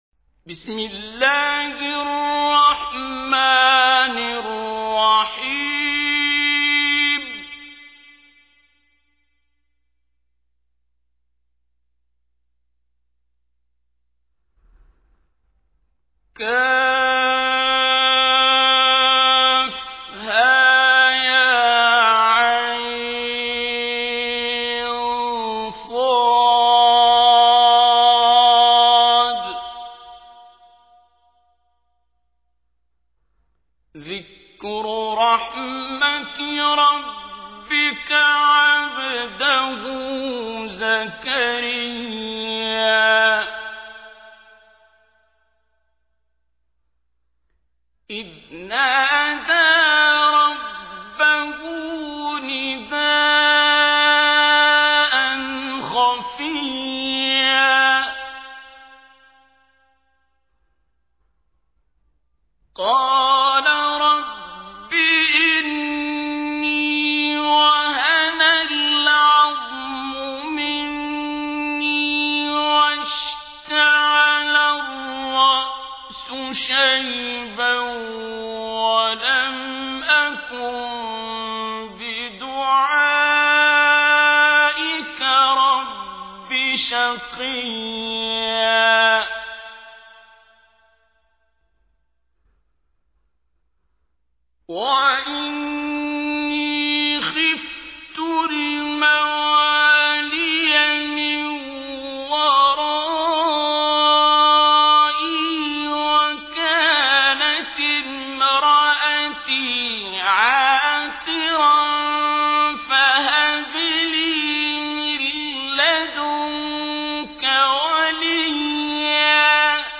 تحميل : 19. سورة مريم / القارئ عبد الباسط عبد الصمد / القرآن الكريم / موقع يا حسين